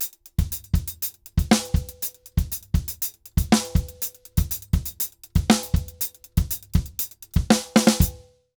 Drums_Salsa 120_1.wav